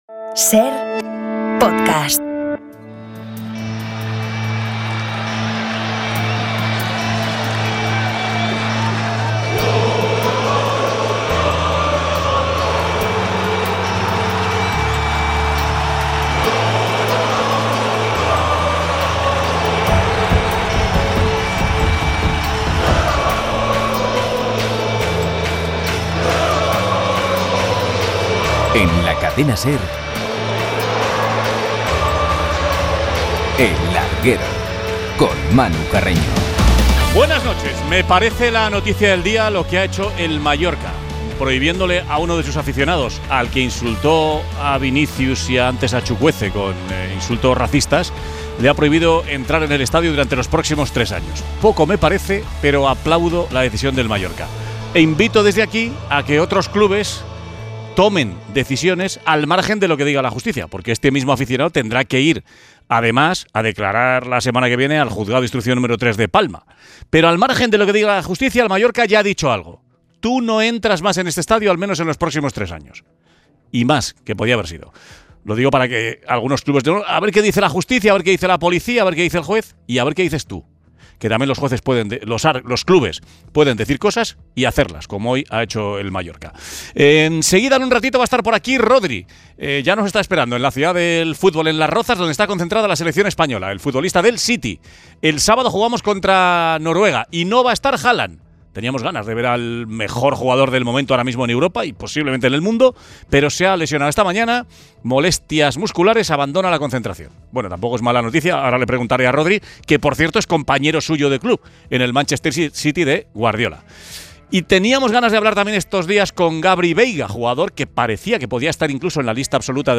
El futuro de Gabri Veiga y entrevista con Rodri Hernández